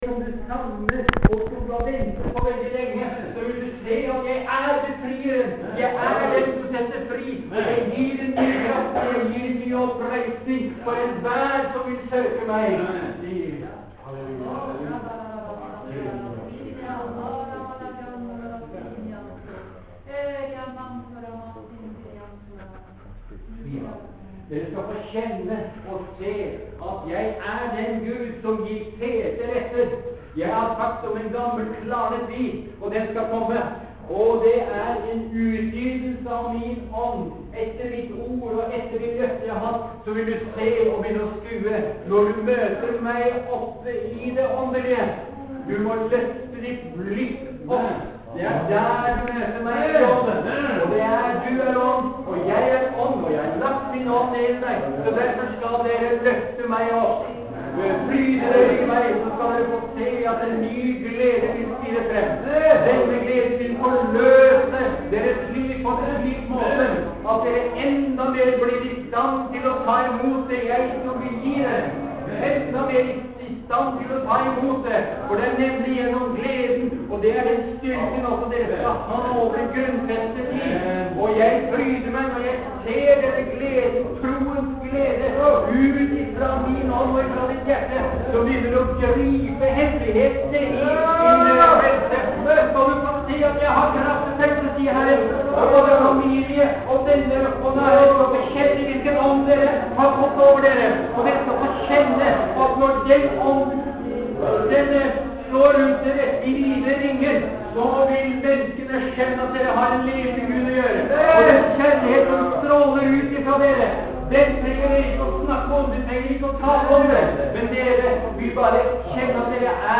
Møte på Misjonshuset på Høvik, Tysdag 12.5.09.